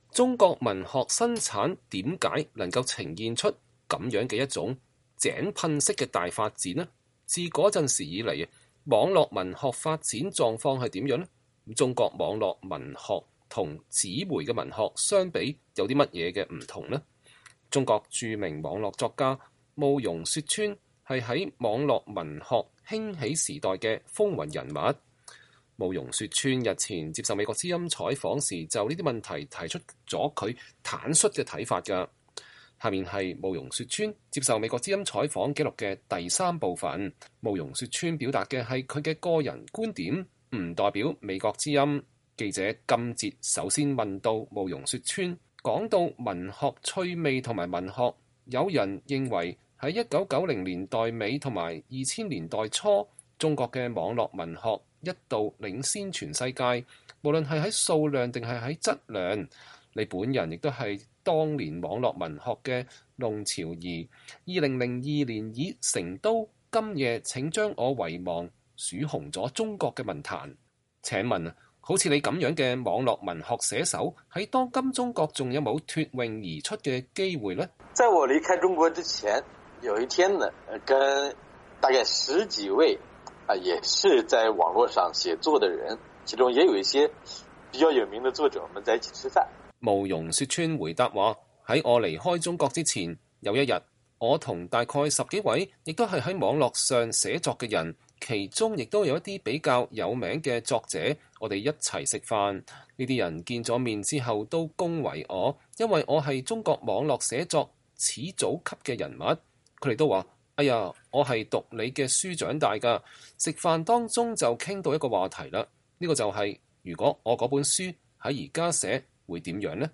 專訪慕容雪村(3)：談當今中國文學